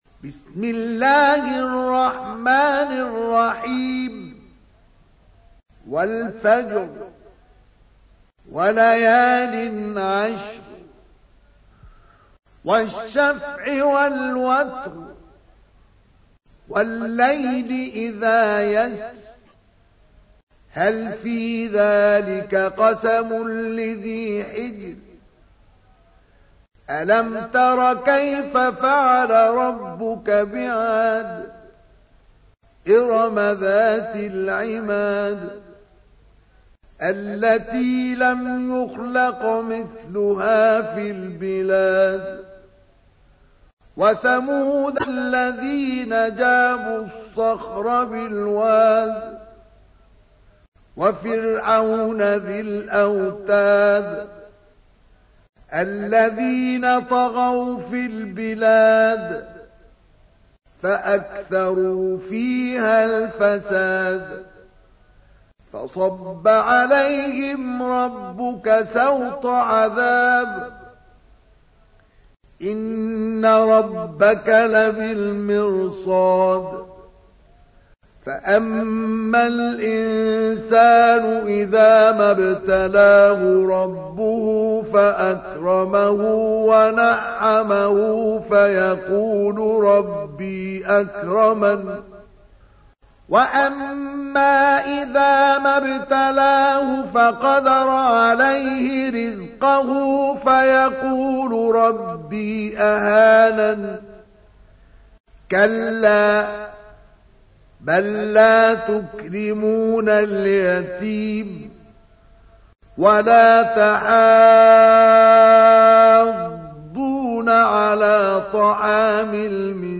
تحميل : 89. سورة الفجر / القارئ مصطفى اسماعيل / القرآن الكريم / موقع يا حسين